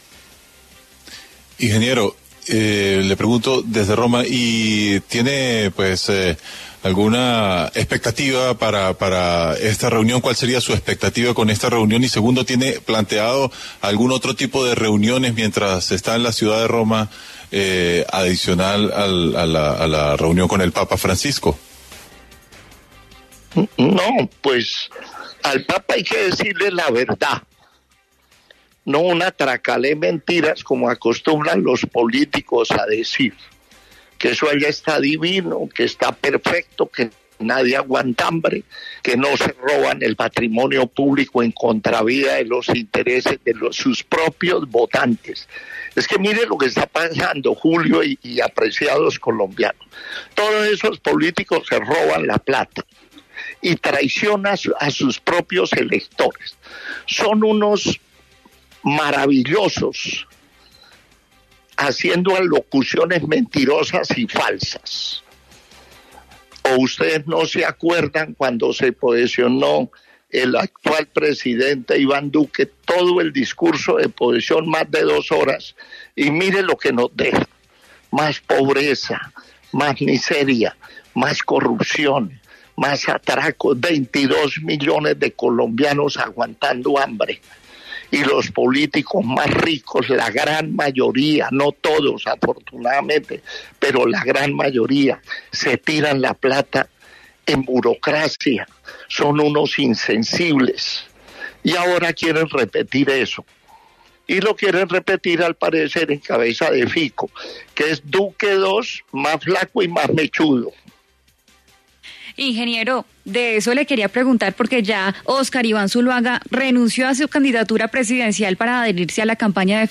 En diálogo con La W, el candidato presidencial Rodolfo Hernández habló de su gira por Europa y la visita que realizará al papa Francisco. Durante la entrevista se refirió al apoyo que Óscar Iván Zuluaga, quien era el candidato presidencial del Centro Democrático, brindó a la campaña de Federico Gutiérrez tras renunciar a su aspiración.